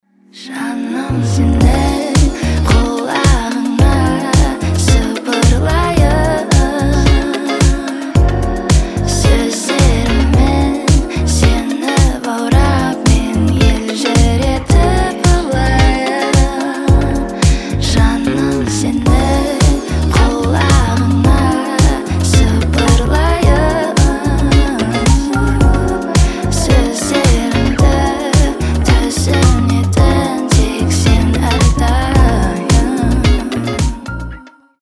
• Качество: 320, Stereo
красивые
женский голос